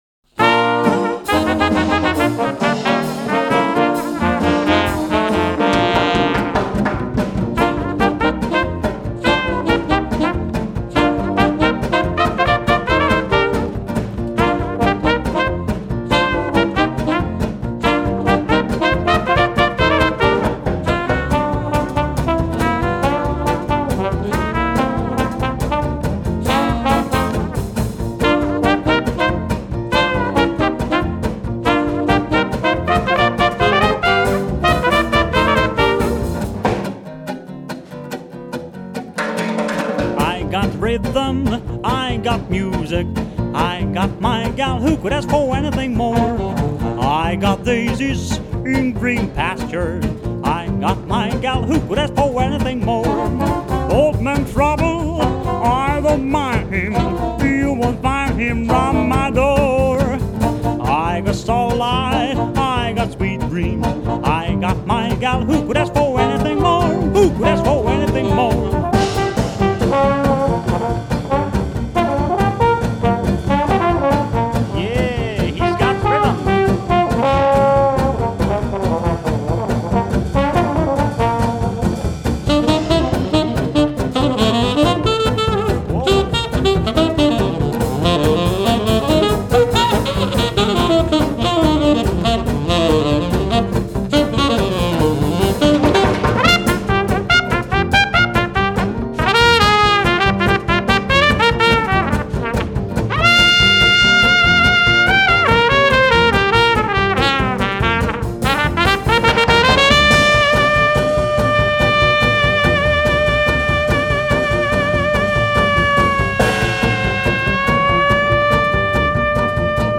Джаз